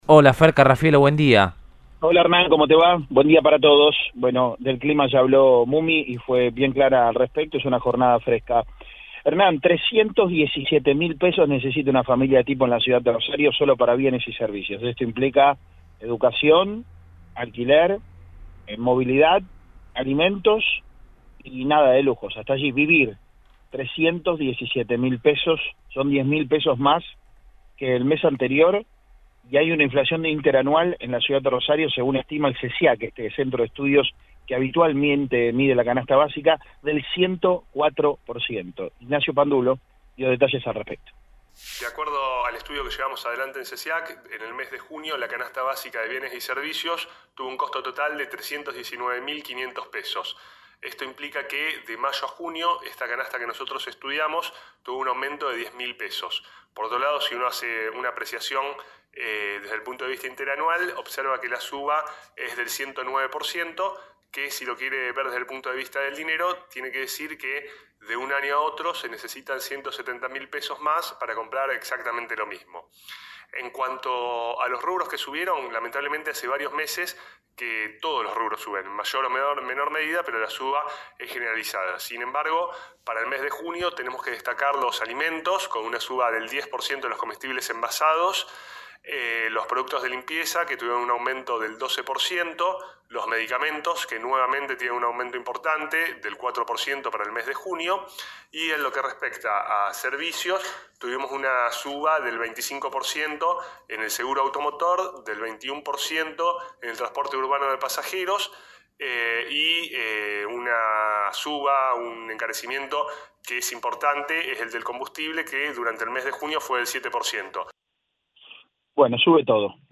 habló con el móvil de Cadena 3 Rosario, en Radioinforme 3, y dio detalles de los aumentos de junio.